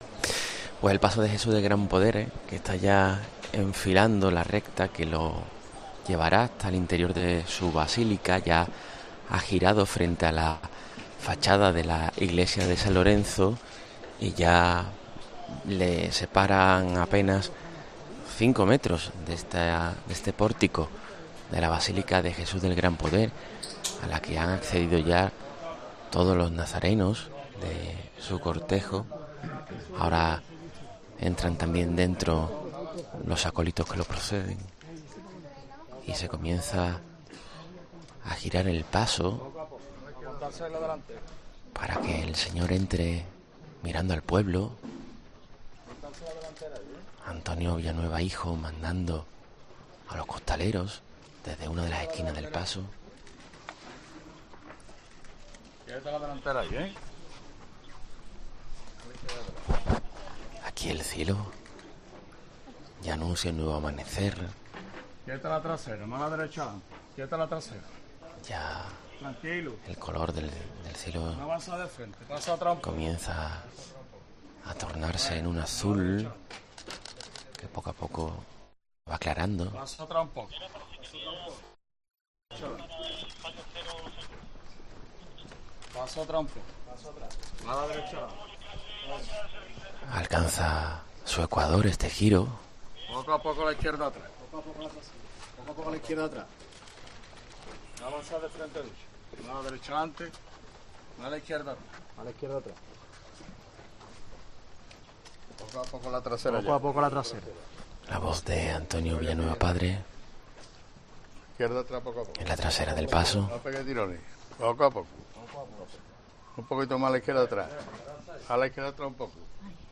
En penumbras y ante una basílica de San Lorenzo repleta: así entraba el 'Gran Poder' a su capilla